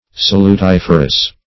Search Result for " salutiferous" : The Collaborative International Dictionary of English v.0.48: Salutiferous \Sal`u*tif"er*ous\, a. [L. salutifer; salus, -utis, health + ferre to bring.] Bringing health; healthy; salutary; beneficial; as, salutiferous air.